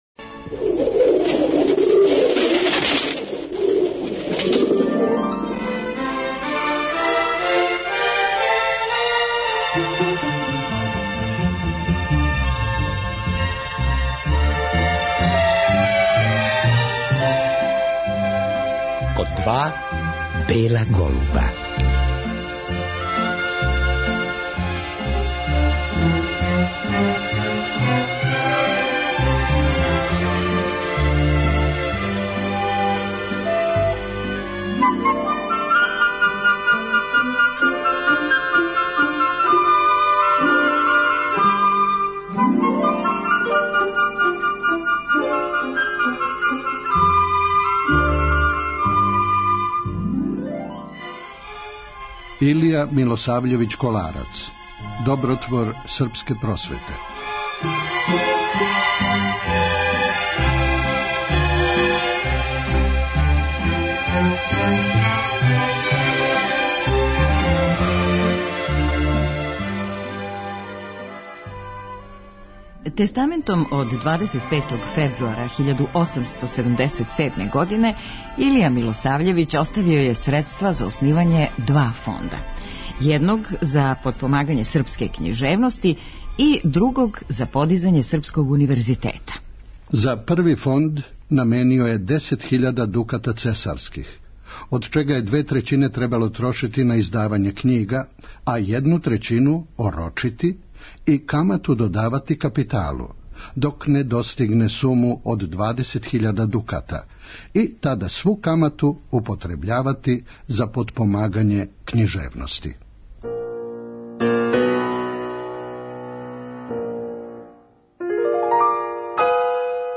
Неке делове из те књиге прочитаћемо у вечерашњој емисији.